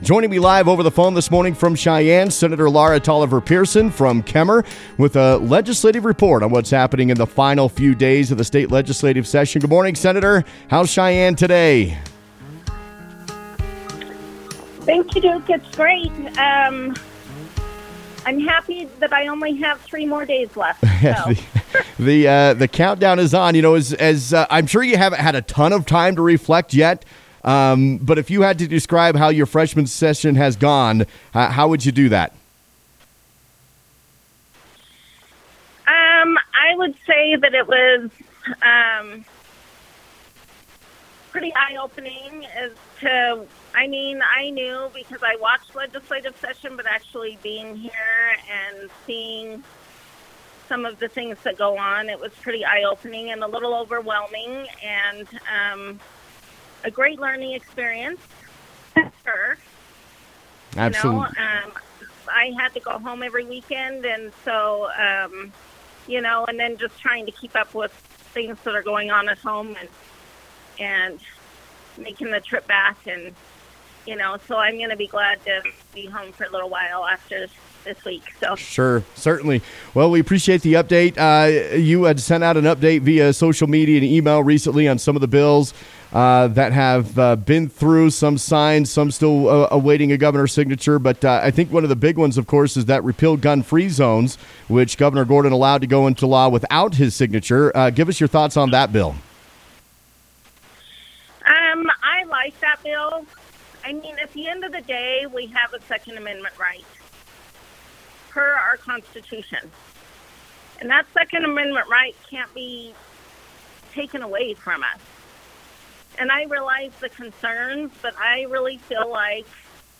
Laura Taliaferro Pearson for Senate District 14 (R-Kemmerer) called into the Weekday Wake-Up on SVI Radio on Tuesday, March 4 with her weekly legislative report.